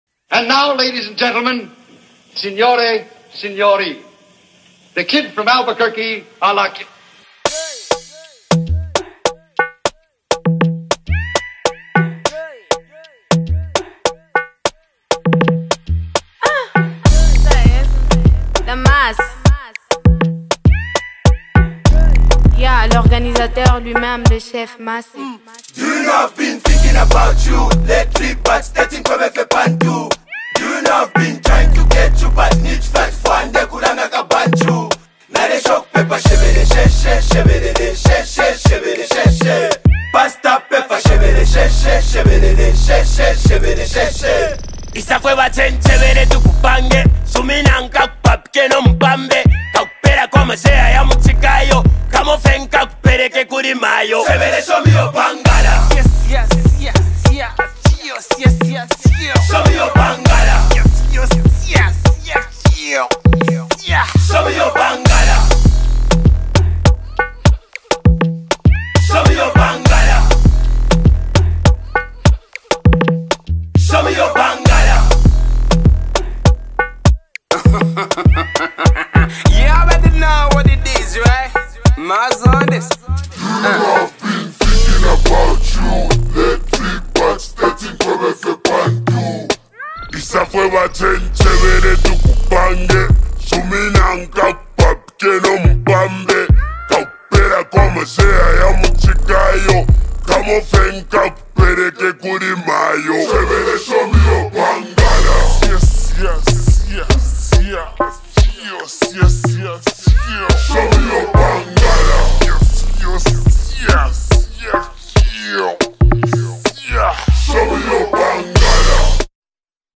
The acclaimed Zambian duo